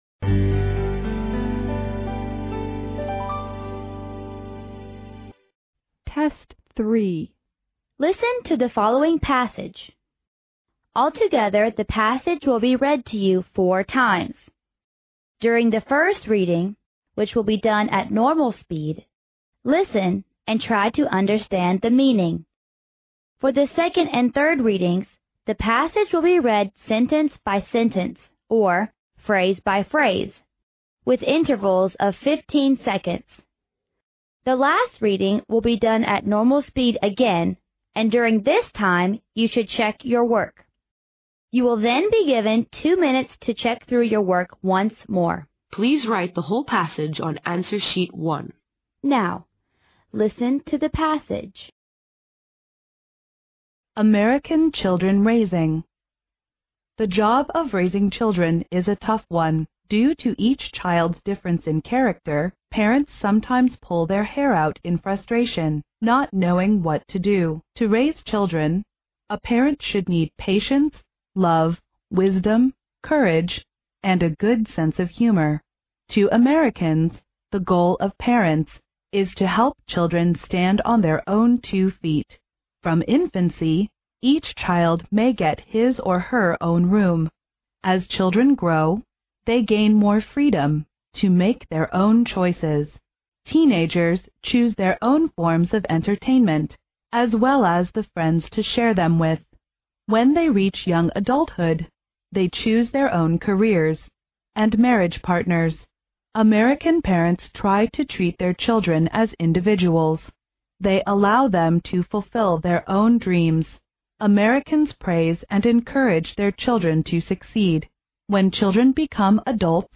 Altogether the passage will be read to you four times. During the first reading, which will be done at normal speed��listen and try to understand the meaning. For the second and third readings��the passage will be read sentence by sentence, or phrase by phrase��with intervals of 15 seconds.